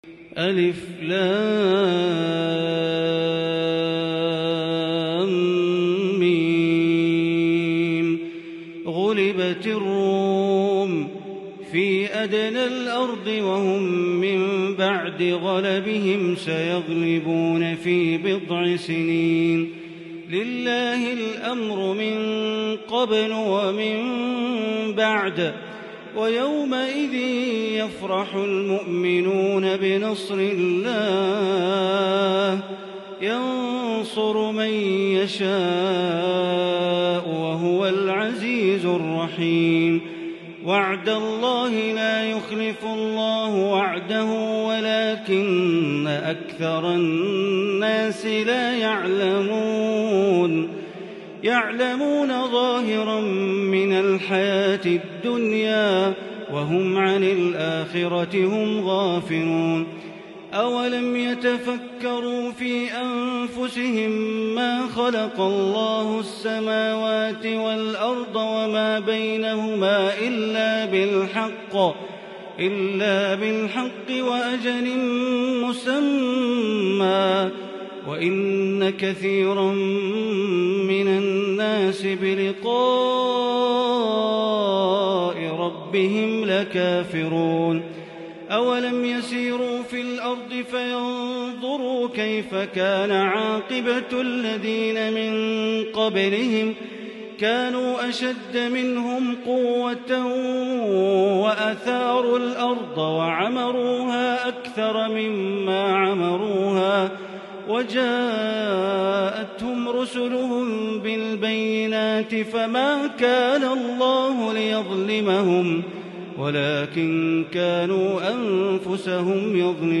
سورة الروم > مصحف الحرم المكي > المصحف - تلاوات بندر بليلة